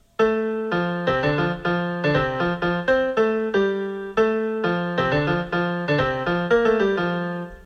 played in its normal key of F
Source Self-Created via Online Sequencer